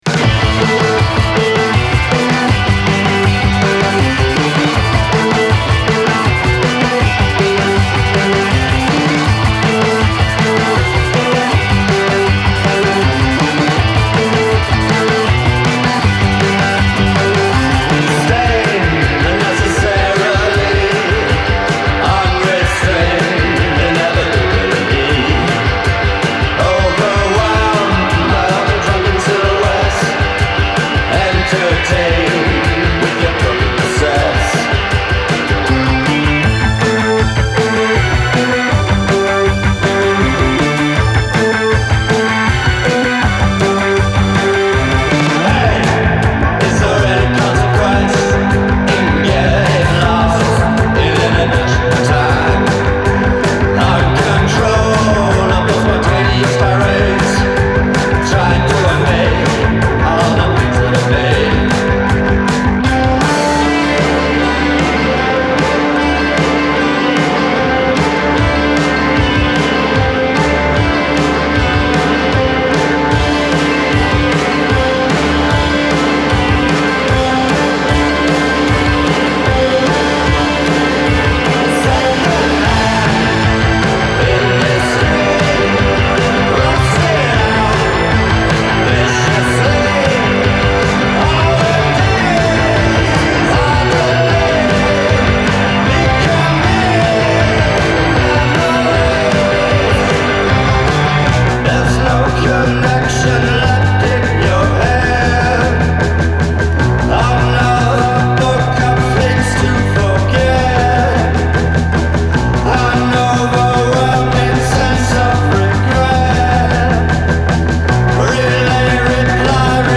sings in a commanding monotone
highly distorted guitars